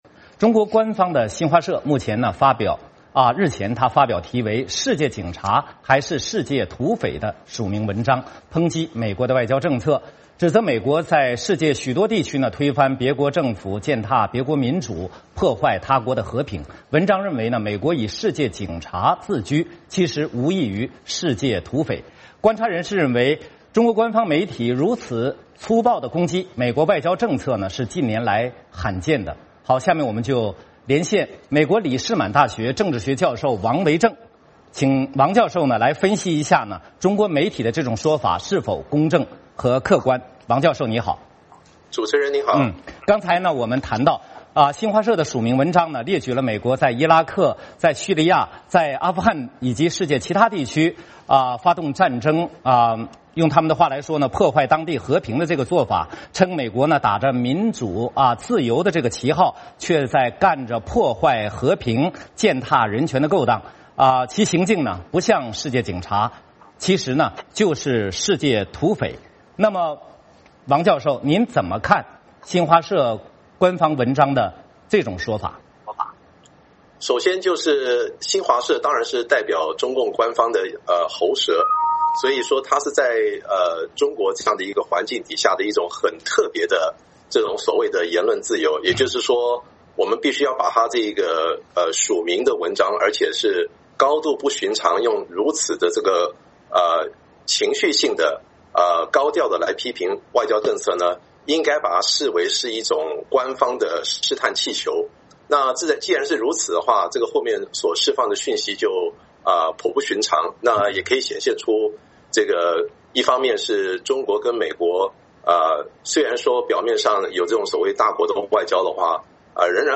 VOA连线：美国咋成了“世界土匪”？